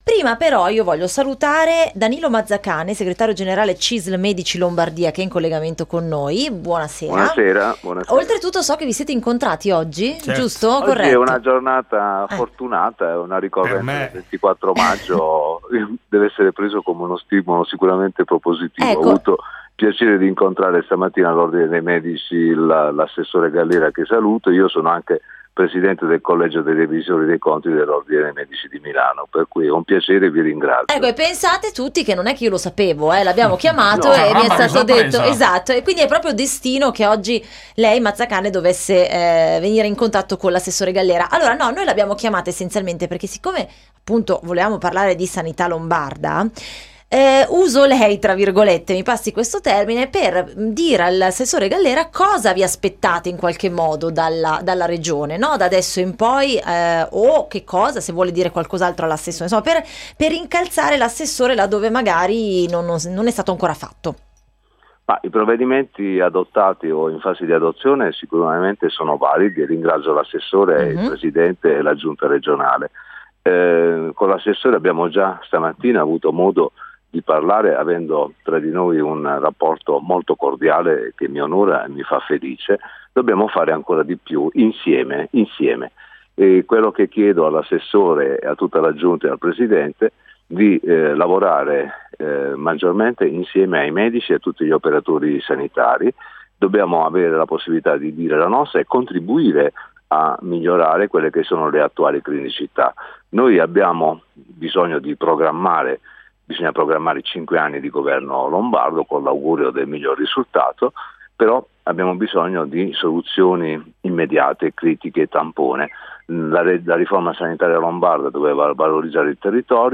Radio – Su Radio Lombardia confronto Cisl Medici-assessore Gallera – CISL Lombardia